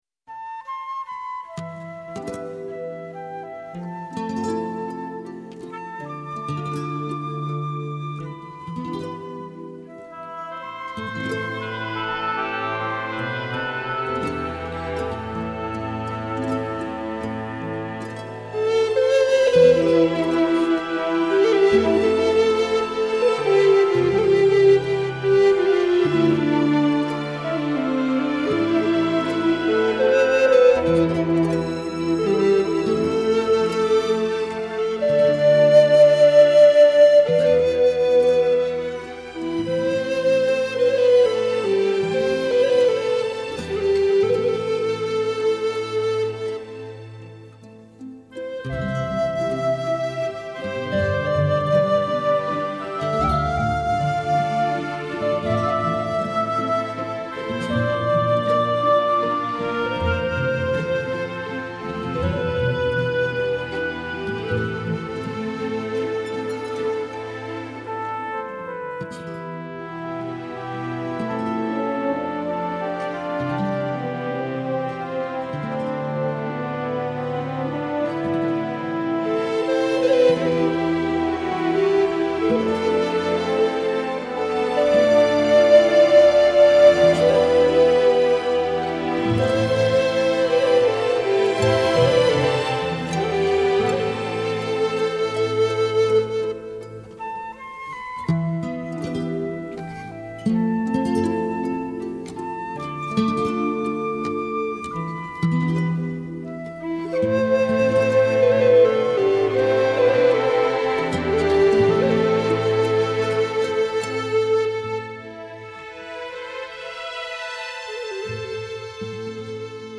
一组清纯飘渺的竖笛曲合辑